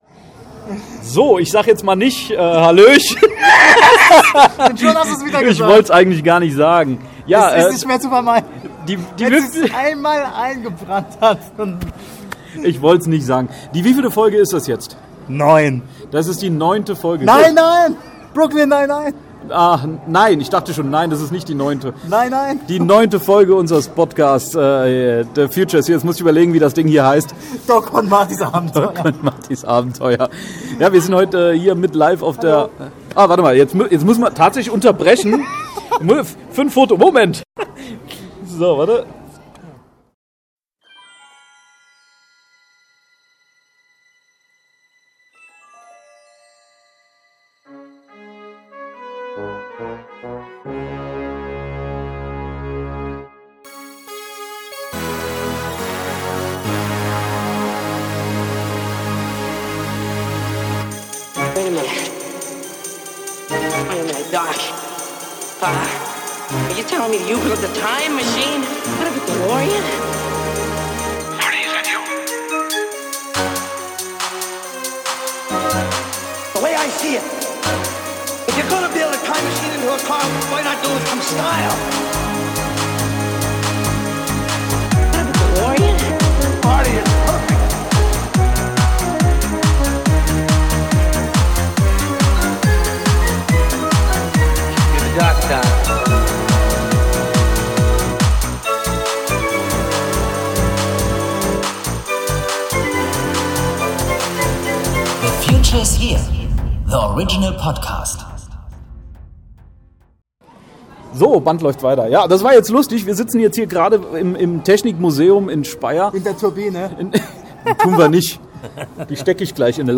Folge Nummer 9 :-) Wir waren auf dem Cosplay-Wochenende im Technikmuseum in Speyer. Aber nicht nur wir, sondern auch noch viele andere verrückte :-) Einige haben wir vor das Mikrofon gezerrt, andere sind freiwillig davor gelaufen.